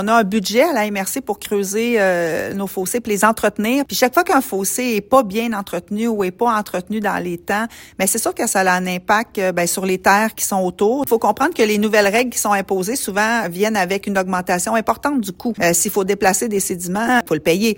Les normes strictes qui doivent être respectées durant les travaux ont fait gonfler la facture totale comme l’a expliqué la préfète de la MRC de Nicolet-Yamaska, Geneviève Dubois.